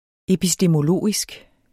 Udtale [ episdəmoˈloˀisg ]